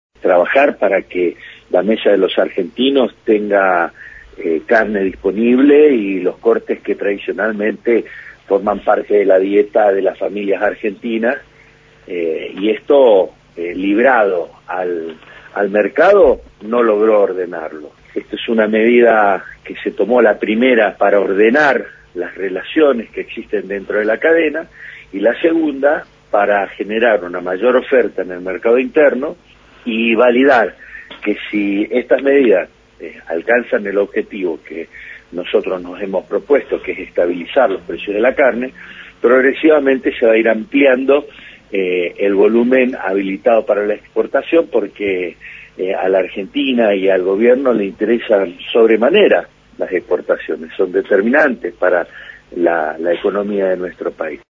En diálogo con Radio Nacional, Basterra dijo que "a la Argentina y al Gobierno le interesan sobremanera las exportaciones, son determinantes para la economía de nuestro país, pero más importante es que las familias argentinas tengan acceso a la carne en precios que sean compatibles con lo que es la evolución de la economía argentina".